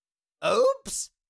Cartoon Little Monster, Voice, Oops Sound Effect Download | Gfx Sounds
Cartoon-little-monster-voice-oops.mp3